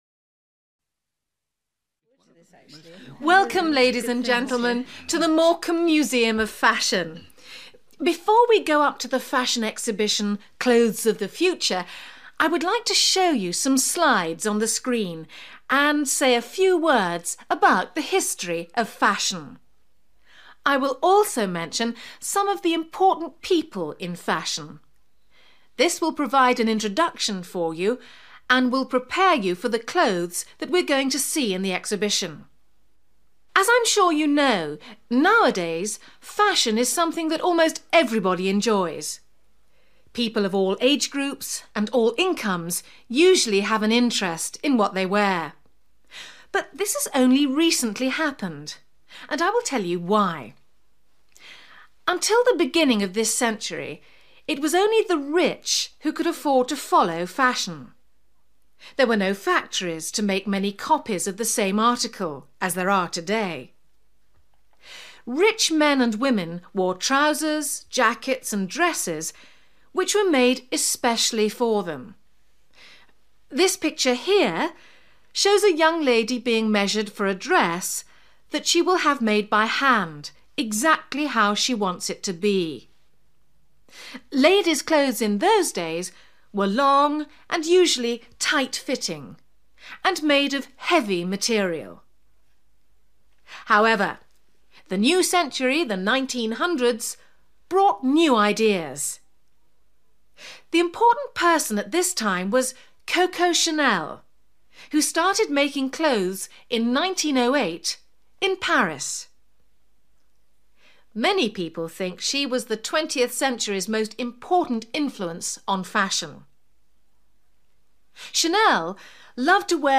You will hear a talk given to visitors to a fashion museum.